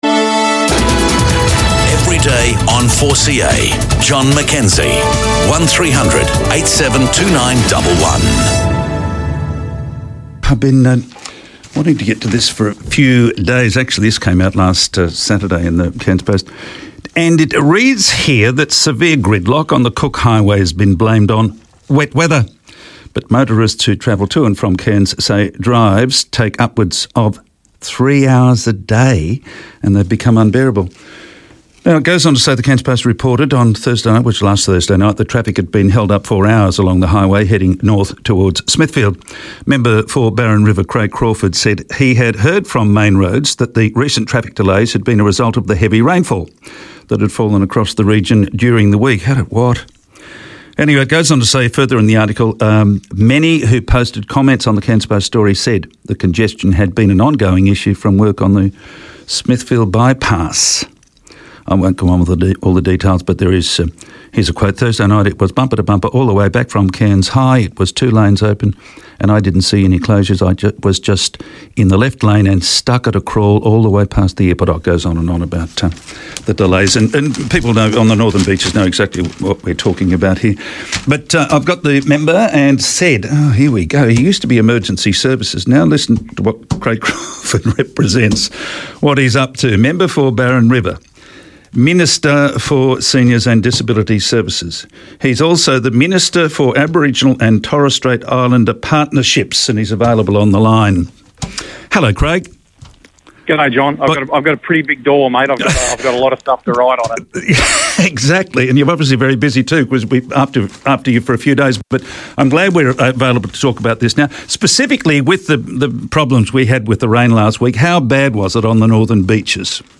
Today I spoke to Craig Crawford, the Member for Barron River, about the latest developments on the Smithfield Highway, Barron River Bridge, and surrounding areas.